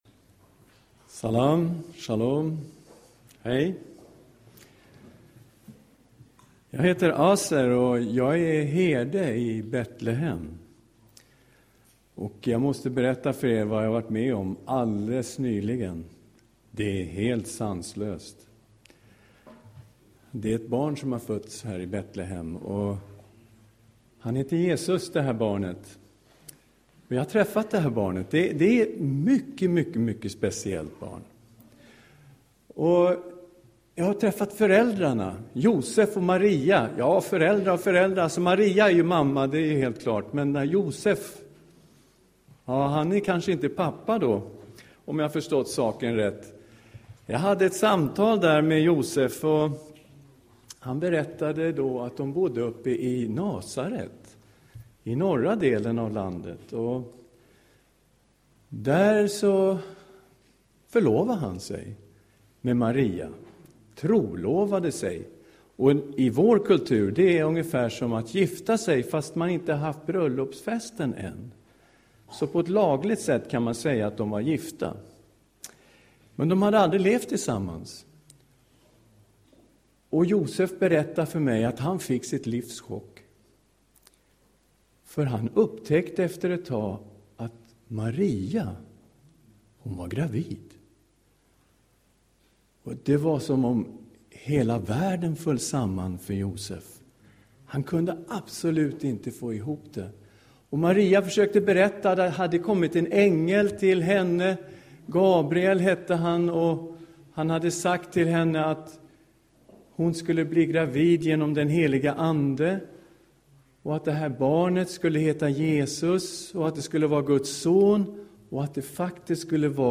En herde berättar julevangeliet